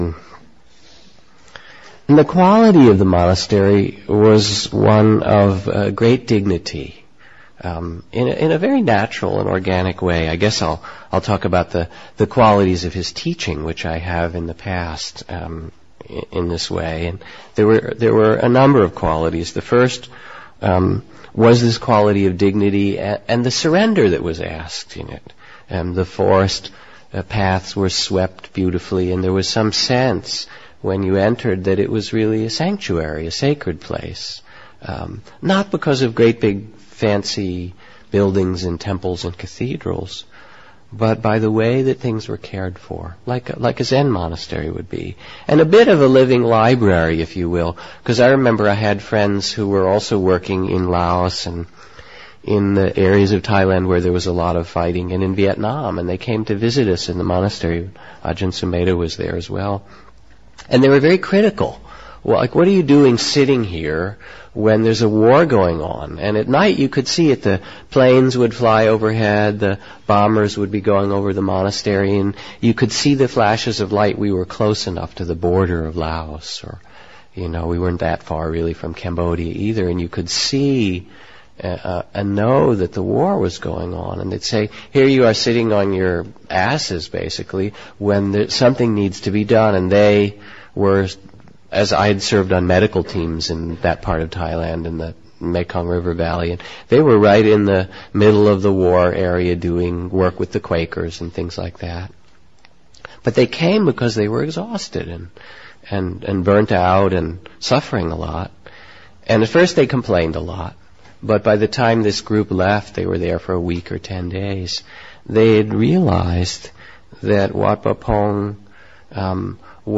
Wat Pah Pong: A place of dignity and surrender. Reflection by Jack Kornfield.